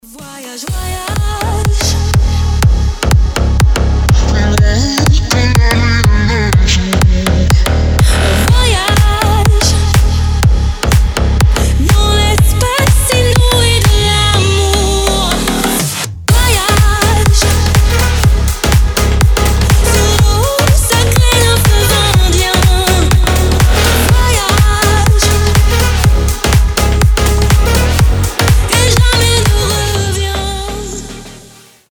• Качество: 320, Stereo
громкие
зажигательные
retromix
future house
ремиксы
Ремикс 2020 года нестареющей танцевальной классики